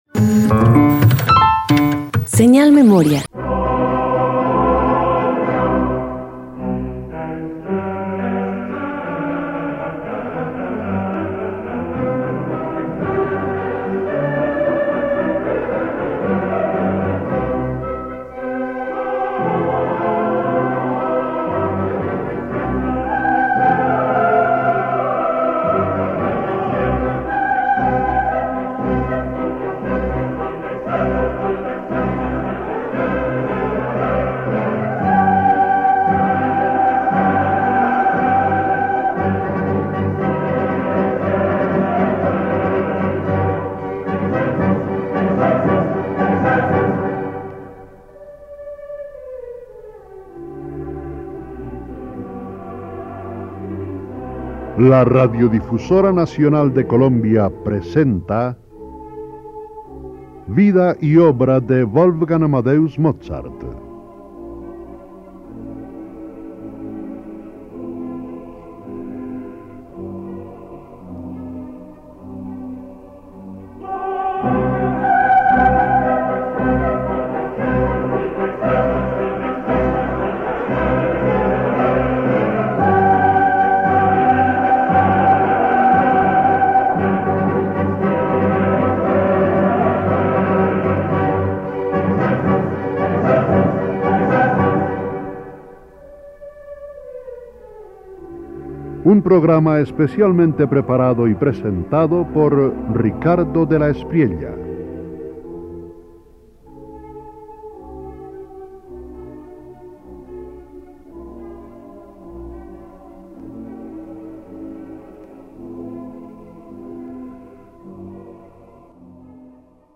Radio colombiana
212 Las seis sonatinas vienesas para piano_1.mp3